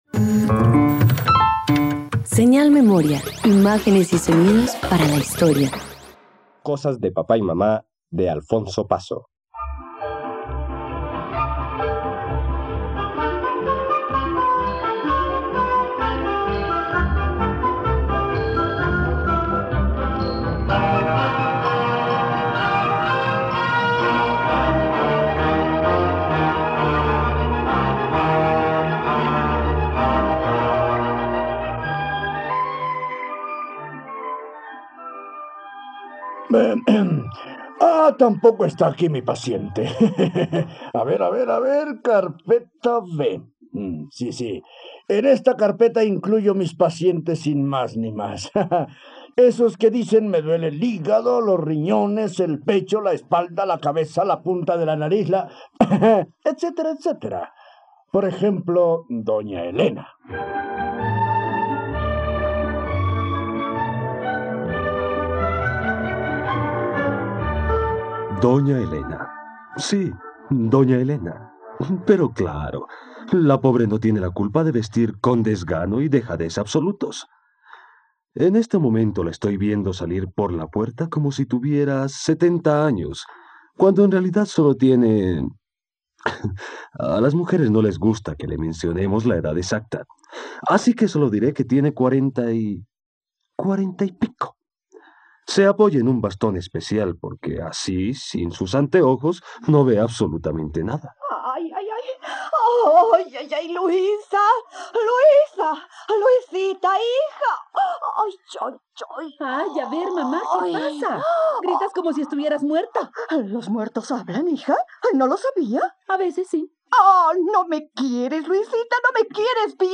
..Radioteatro. En 'Cosas de Papá y mamá' de Alfonso Paso, una pareja de viudos hipocondriacos encuentra el amor, desencadenando hilarantes enredos familiares.